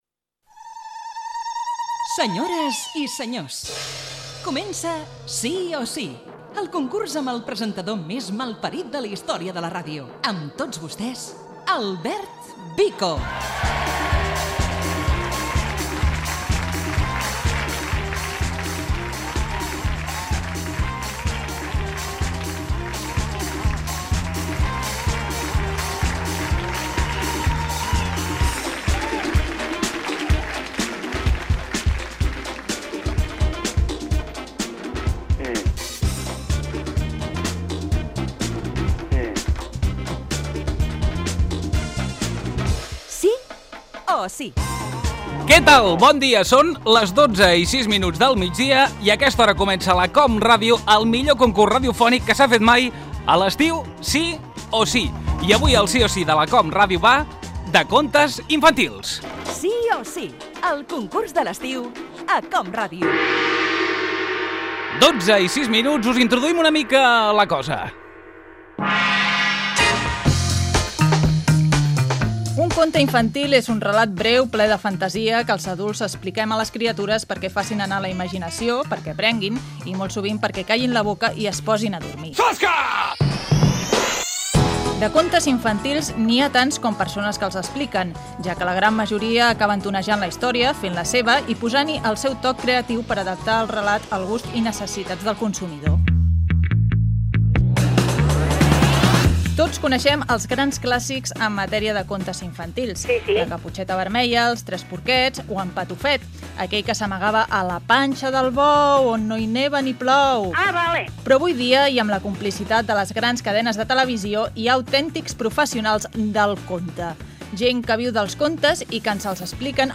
Definició de conta, participació d'una oïdora. Hi col·labora el músic Manu Guix